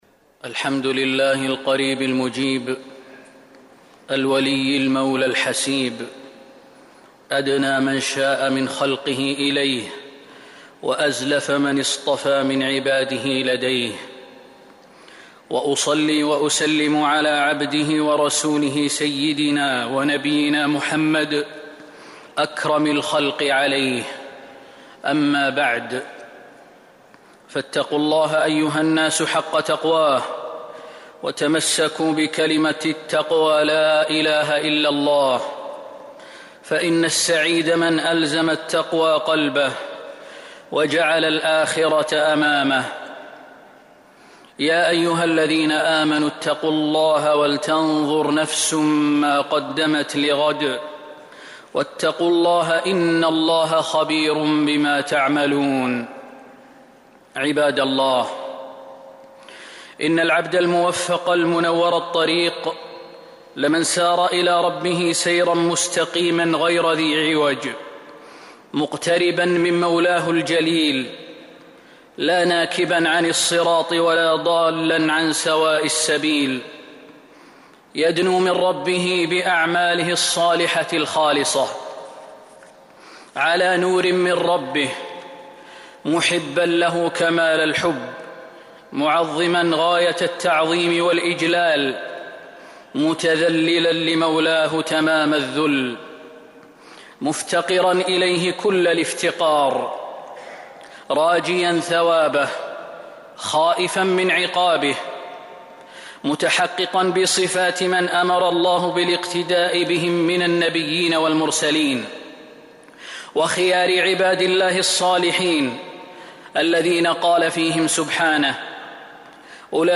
جودة عالية. التصنيف: خطب الجمعة